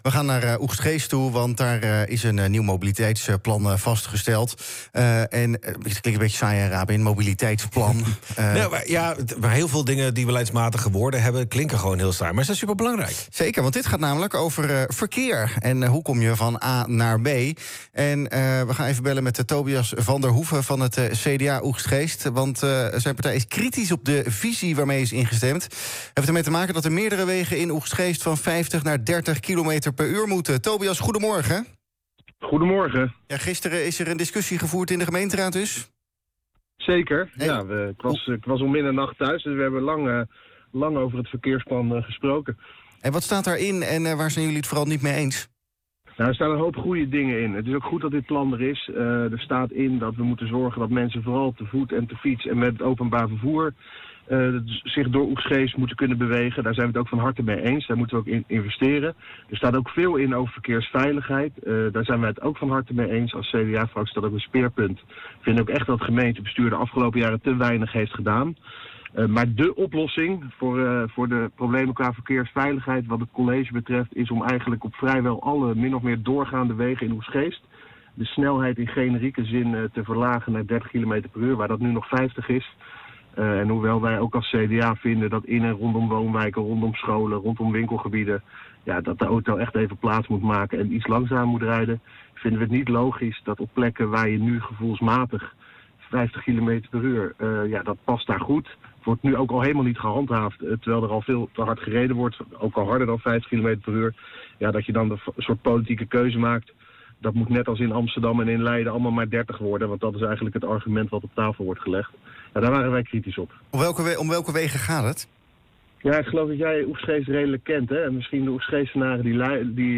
in de ochtendshow